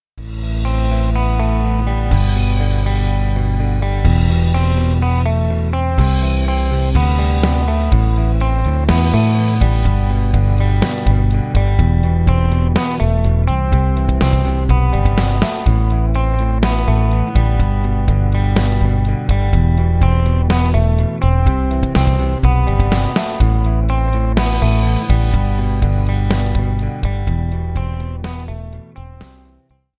Recueil pour Violoncelle